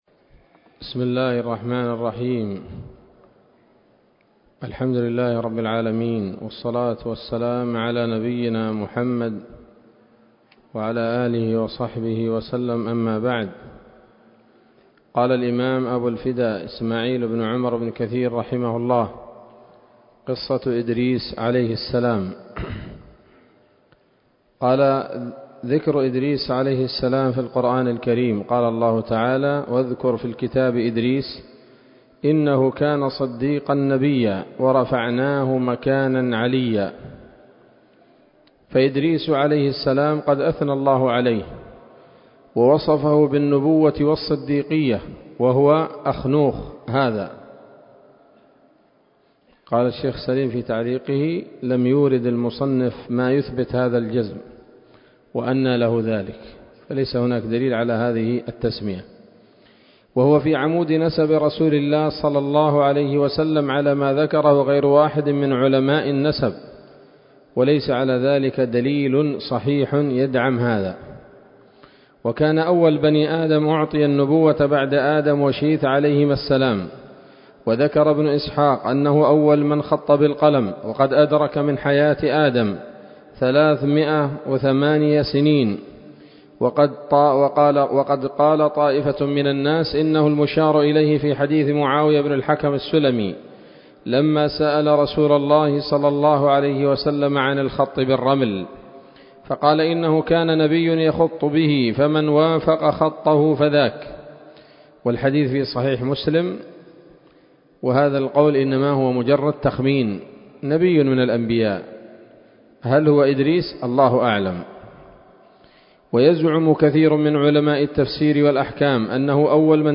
الدرس التاسع عشر من قصص الأنبياء لابن كثير رحمه الله تعالى